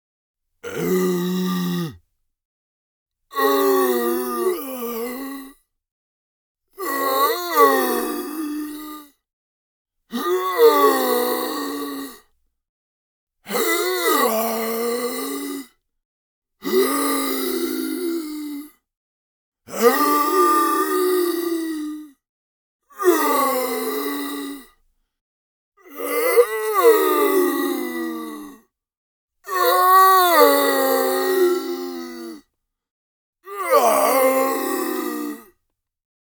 Curious Zombies Moan Medium